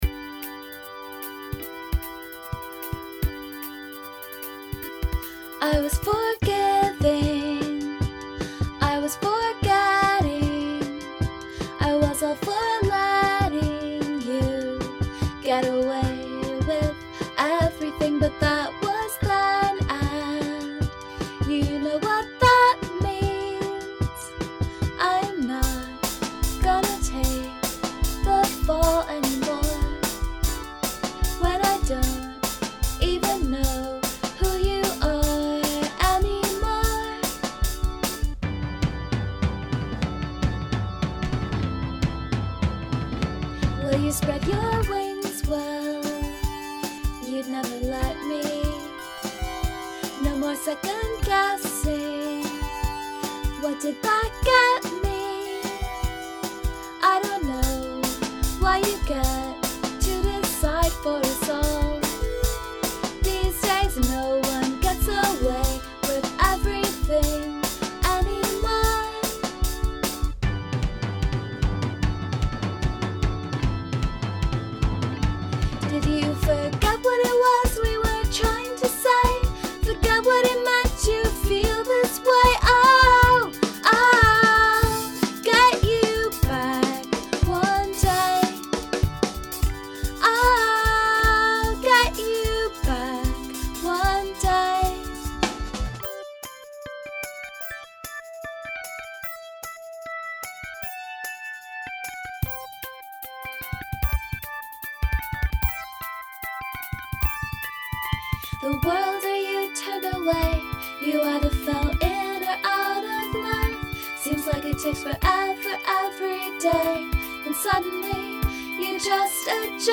fun beats all around, too.